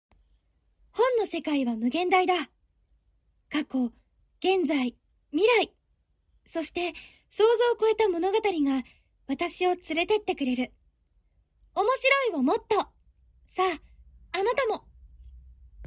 ボイスサンプル
CM１